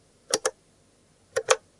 描述：单击并单击按钮。
Tag: 机械 开关 按钮 单击